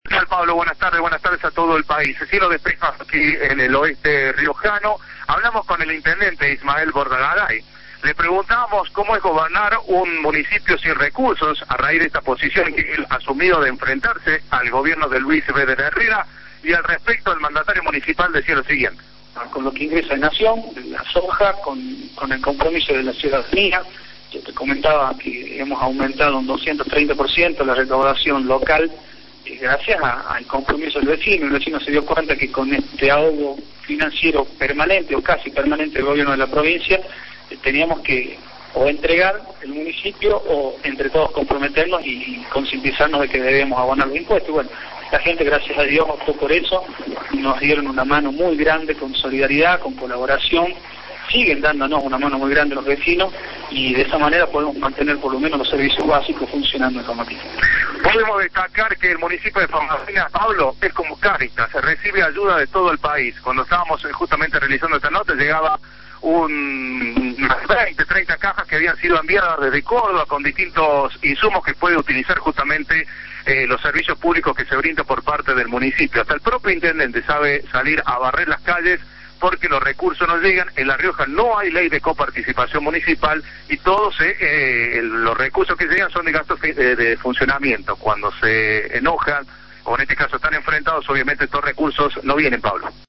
FAMATINA (Enviados Especiales).- El intendente de Famatina, Ismael Bordagaray, comentó a radio Cadena 3 Argentina que gracias al compromiso de los ciudadanos con el pago de impuestos, se puede llevar adelante el municipio, pese a la falta de fondos por parte del gobierno provincial por el conflicto minero.